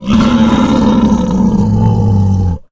boar_death_0.ogg